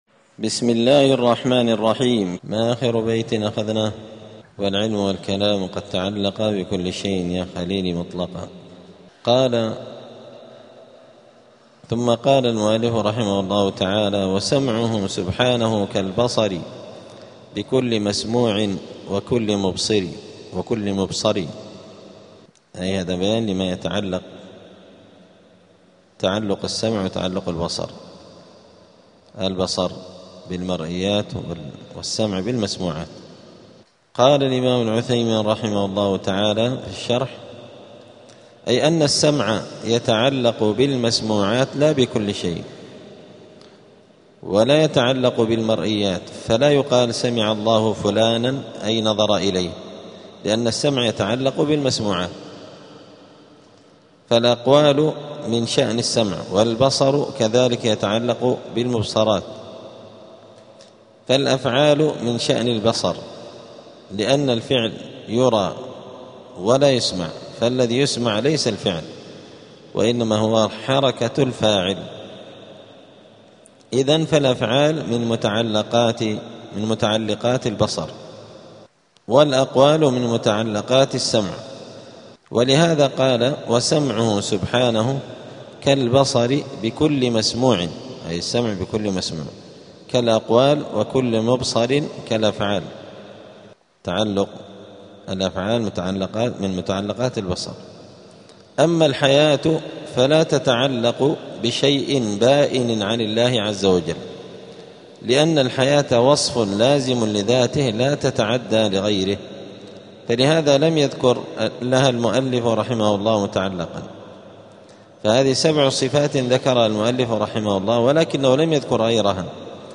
دار الحديث السلفية بمسجد الفرقان قشن المهرة اليمن
38الدرس-الثامن-والثلاثون-من-شرح-العقيدة-السفارينية.mp3